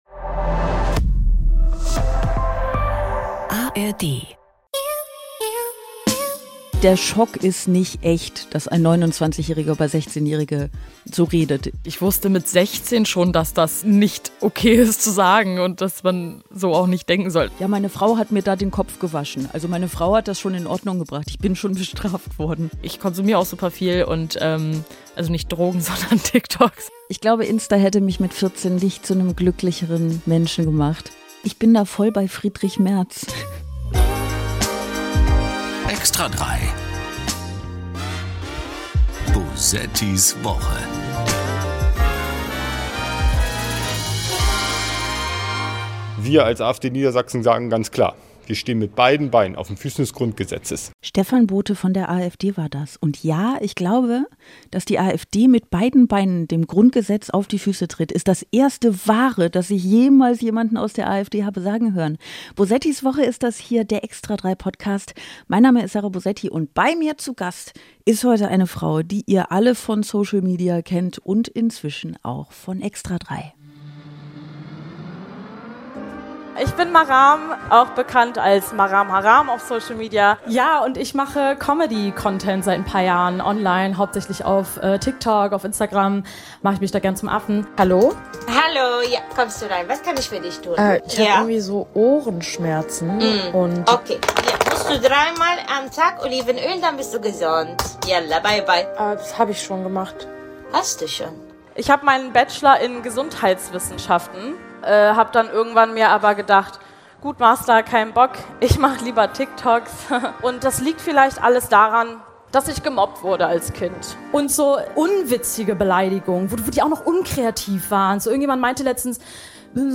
Ein angeregter Talk über politische Doppelmoral und männliche Perspektiven-Blindheit. Und dann geht es um das geplante Social Media Verbot für Jugendliche unter 14.